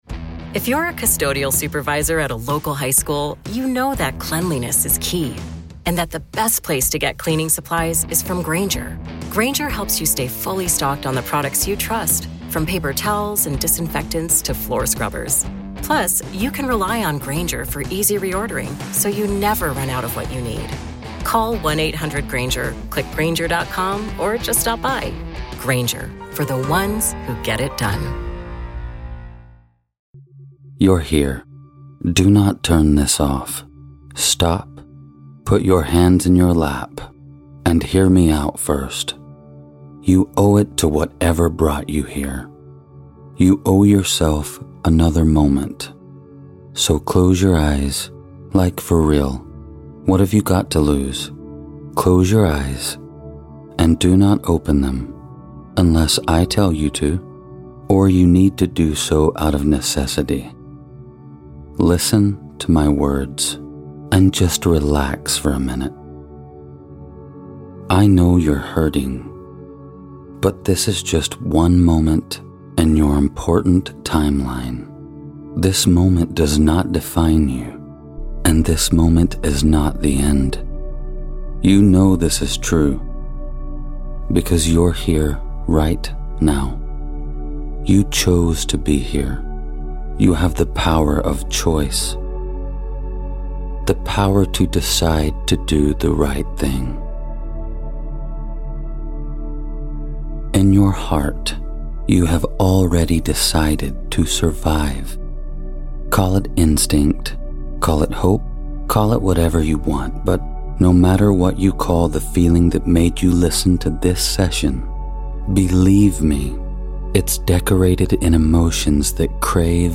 Meditation Station (Sleep Hypnosis